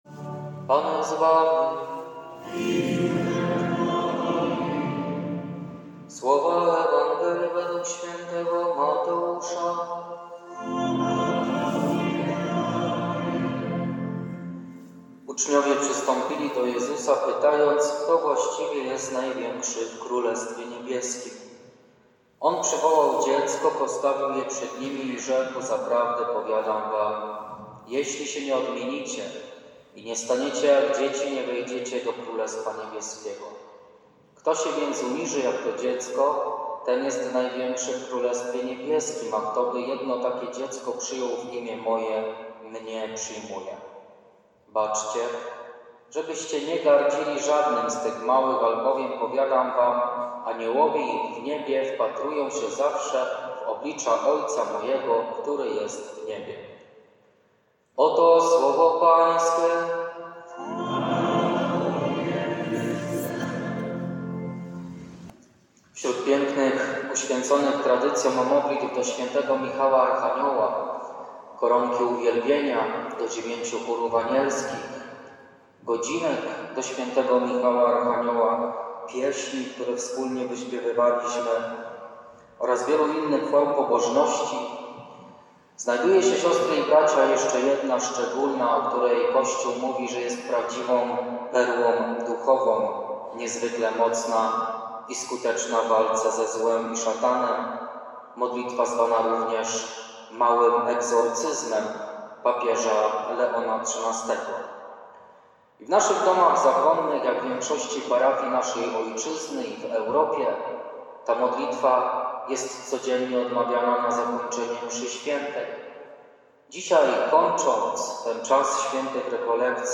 EWANGELIA I HOMILIA
Ewangelia-i-homilia-18.11.mp3